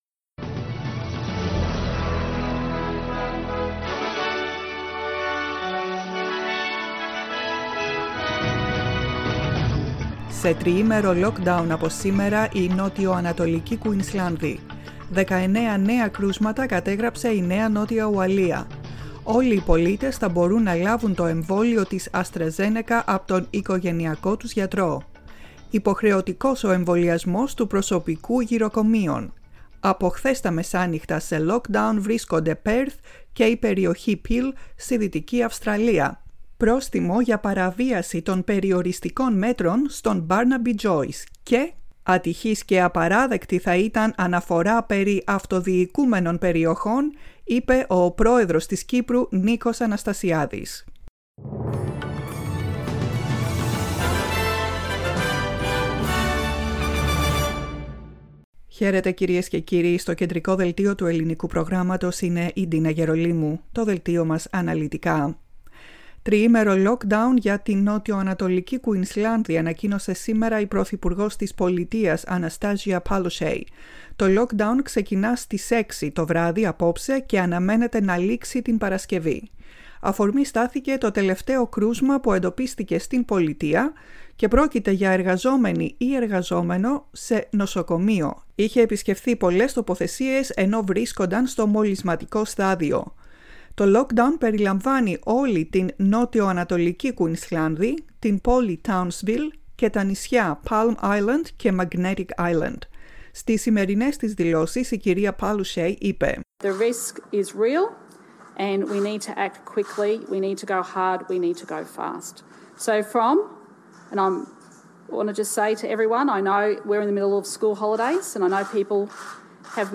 Δελτίο ειδήσεων, 29.06.21
Ακούστε το κεντρικό δελτίο ειδήσεων του Ελληνικού Προγράμματος.